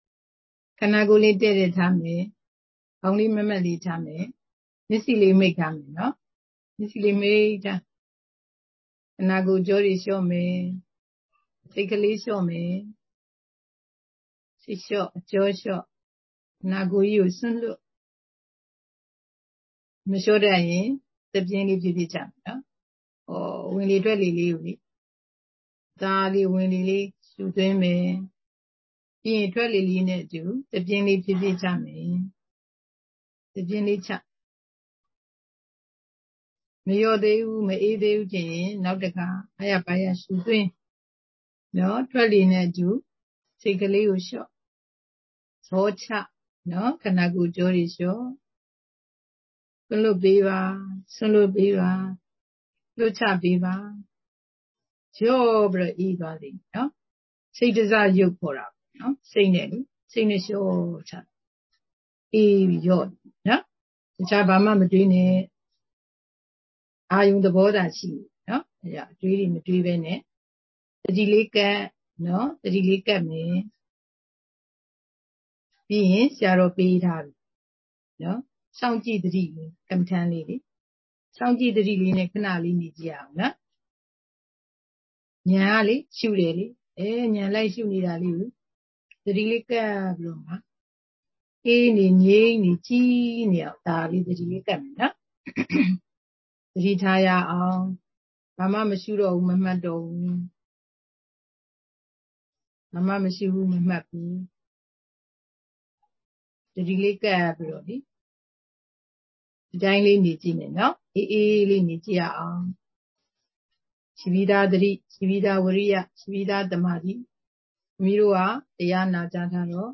Jan04 2023 ညစဉ်တရားပွဲ ဆရာမကြီး နာဂုဏ်ကြီး၊ နာဂုဏ်ငယ် အပိုင်း ၂